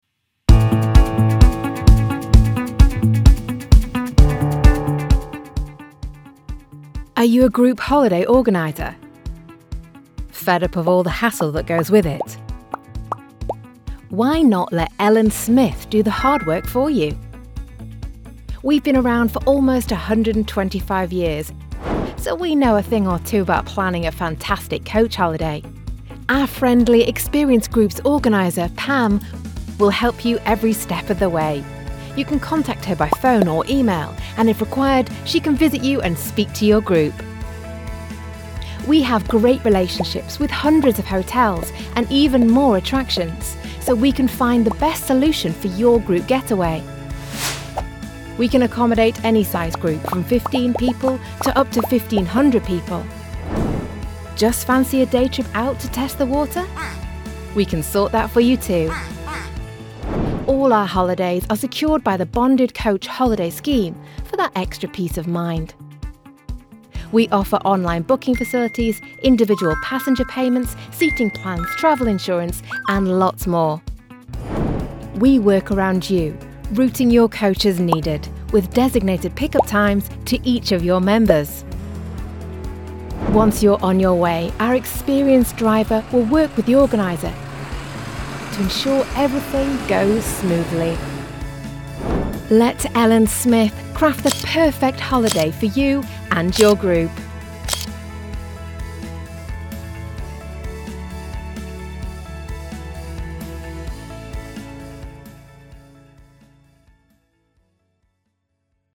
Natural, Versátil, Amable
Corporativo
She has a broadcast quality home studio and is a popular choice amongst clients.